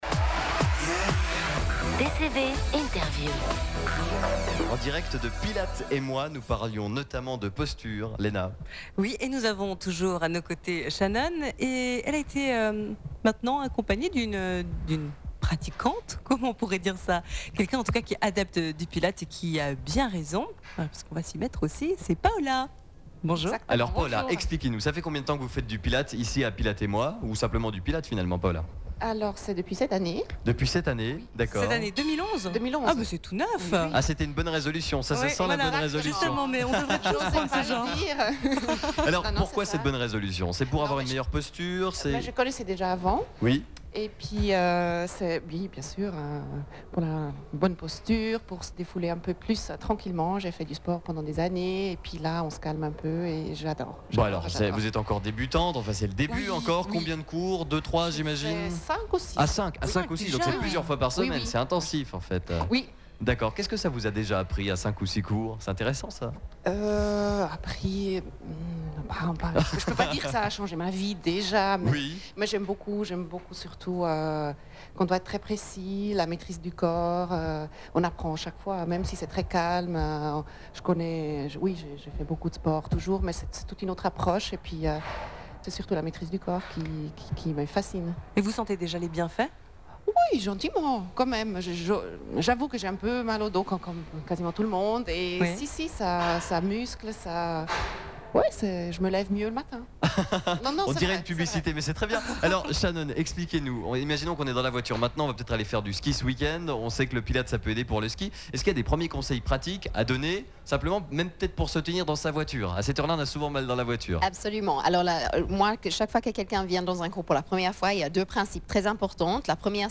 (interview client + pilates & posture)